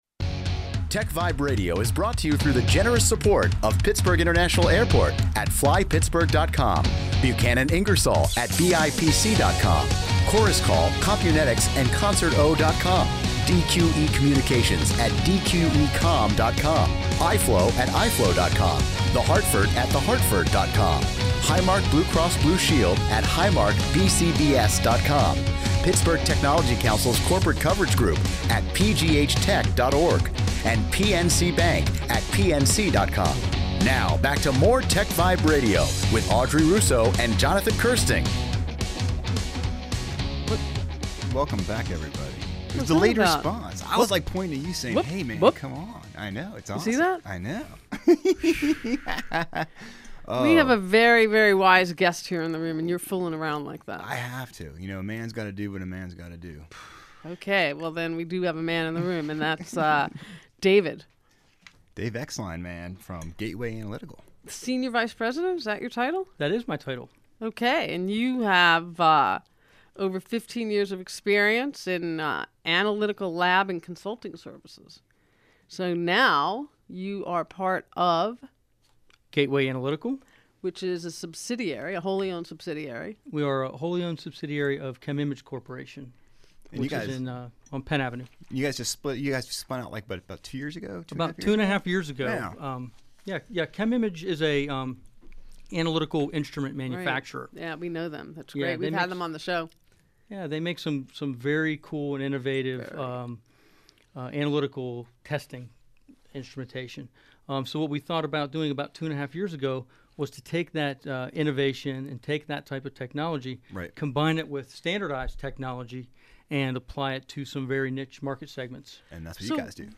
Gateway Analytical Interview on Pittsburgh Technology Council’s TechVibe Radio
TechVibe Radio Interview.mp3